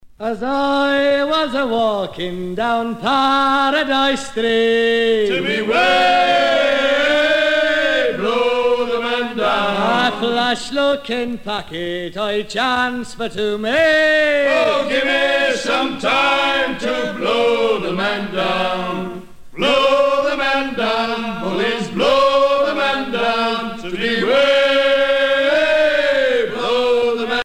maritimes
Pièce musicale éditée